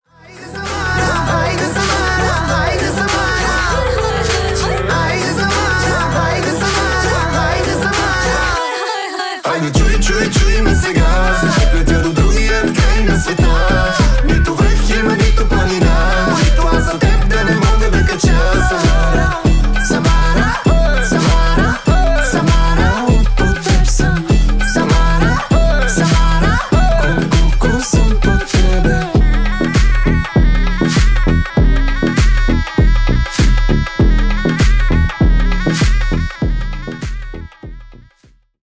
• Качество: 320 kbps, Stereo
Танцевальные